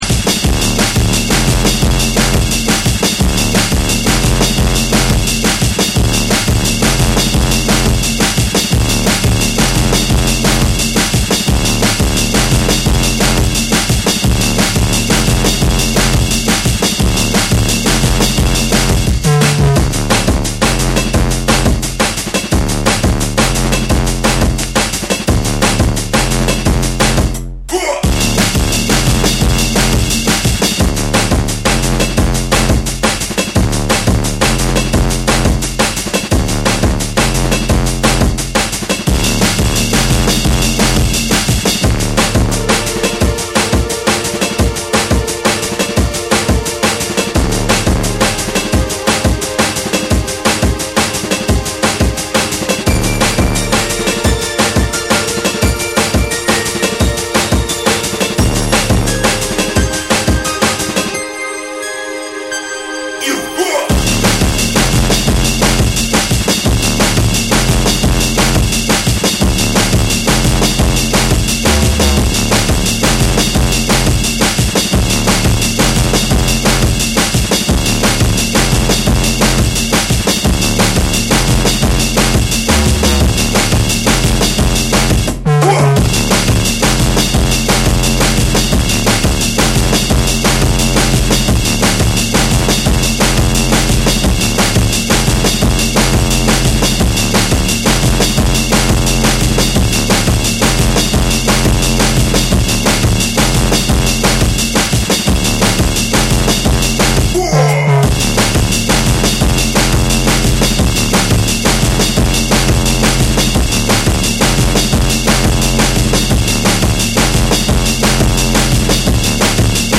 90sドラムンベースを象徴する伝説の女性デュオ
タイトなビート、ダークでハードなベースライン、そして張り詰めた緊張感。
JUNGLE & DRUM'N BASS